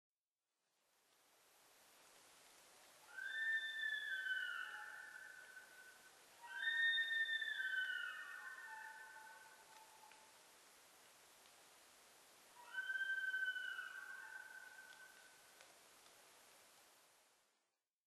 ニホンジカ　Cervus nipponシカ科